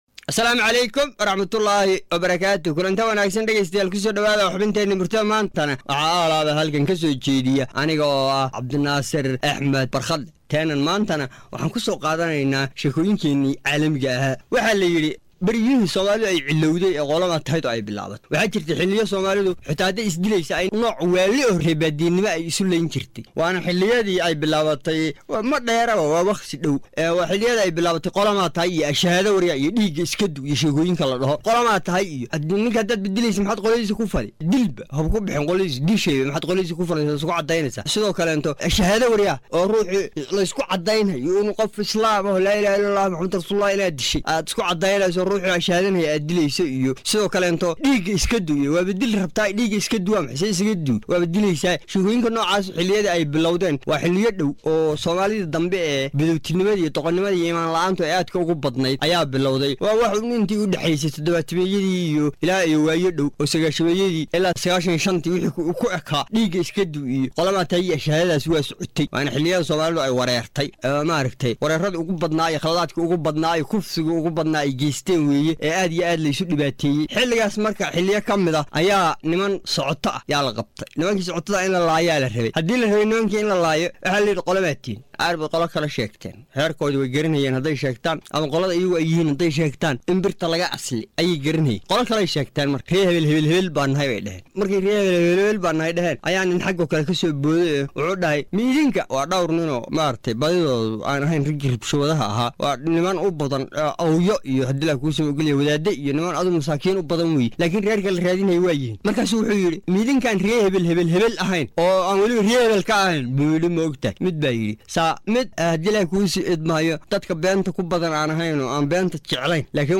Soo jeedinta abwaan